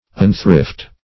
Meaning of unthrift. unthrift synonyms, pronunciation, spelling and more from Free Dictionary.
Search Result for " unthrift" : The Collaborative International Dictionary of English v.0.48: Unthrift \Un"thrift`\, n. 1.